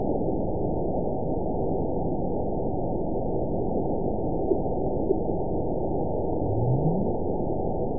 event 921815 date 12/19/24 time 06:41:29 GMT (4 months, 2 weeks ago) score 9.59 location TSS-AB01 detected by nrw target species NRW annotations +NRW Spectrogram: Frequency (kHz) vs. Time (s) audio not available .wav